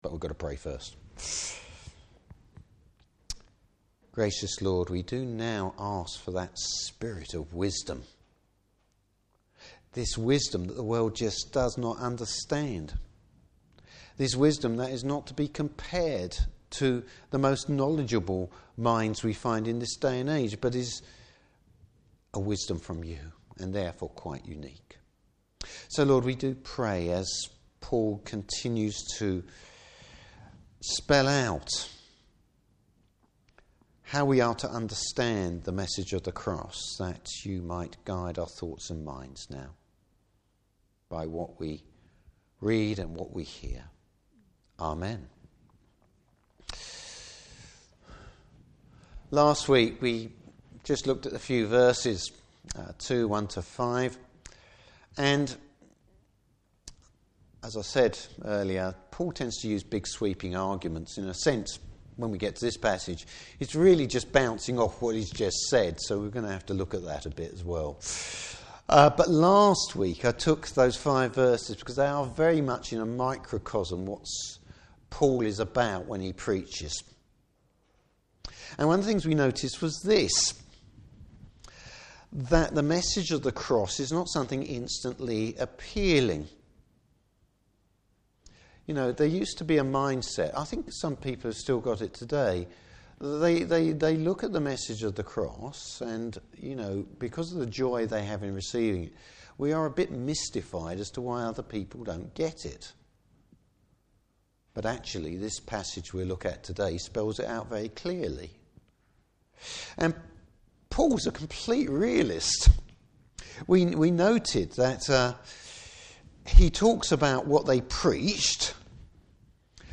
Service Type: Morning Service God’s wisdom revealed through his Spirit.